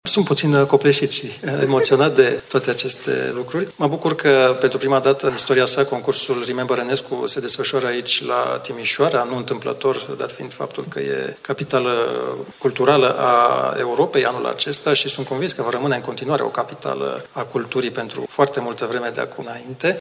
Maestrul Alexandru Tomescu, mentorul celei de-a 21-a ediții a concursului internațional Remember Enescu, organizat în acest an la Timișoara, s-a declarat foarte onorat pentru decorația care urmează să îi fie acordată în cadrul unei ceremonii oficiale.